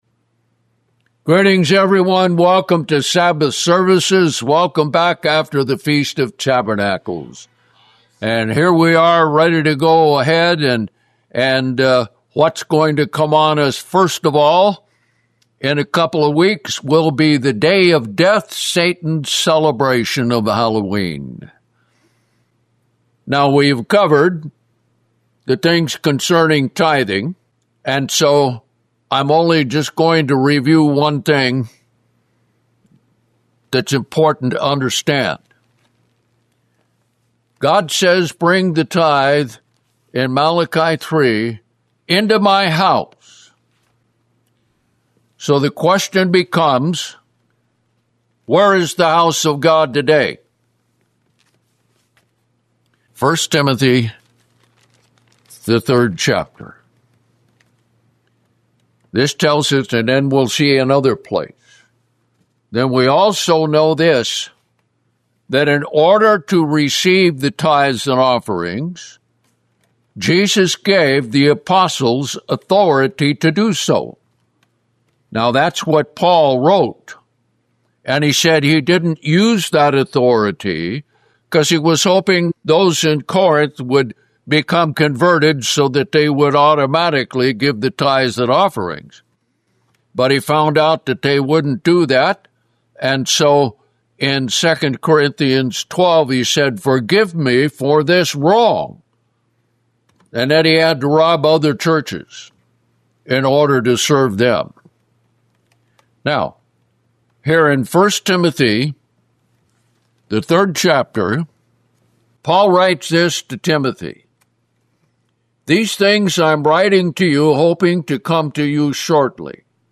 Greetings, everyone, welcome to Sabbath services.